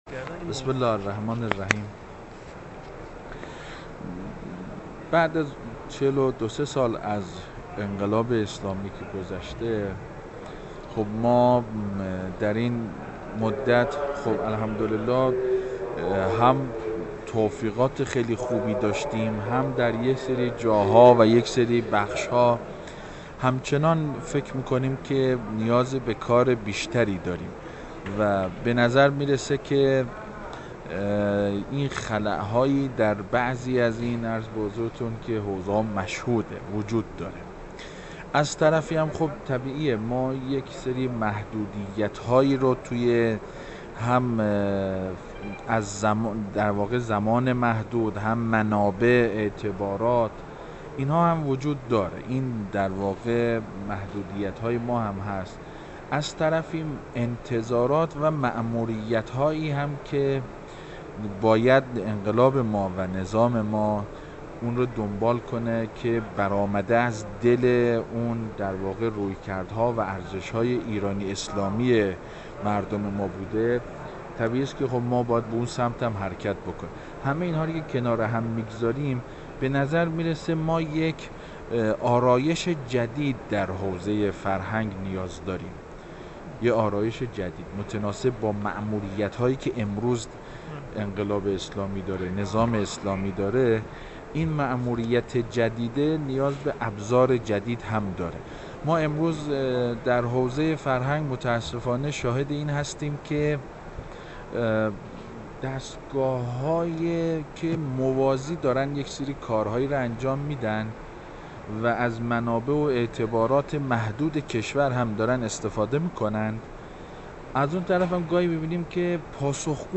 گفتوگو با حجت‌الاسلام مجید نصیرایی